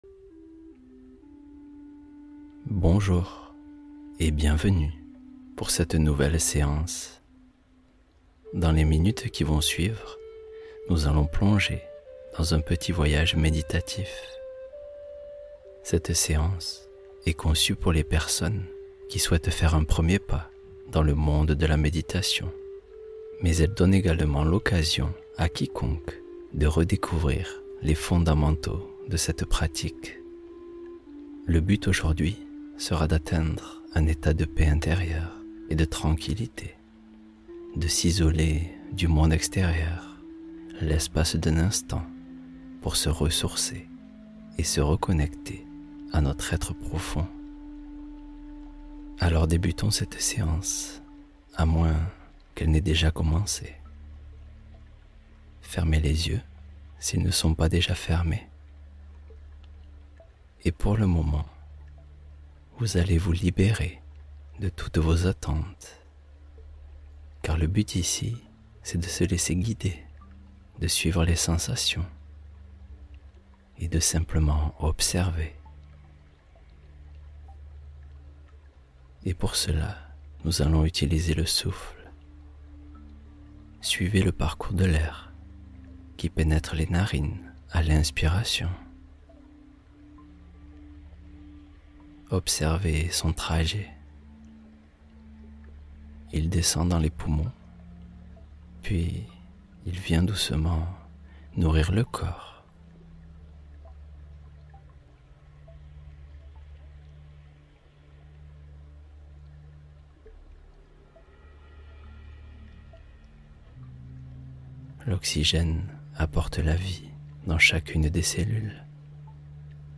Histoire guidée — Accompagner doucement le sommeil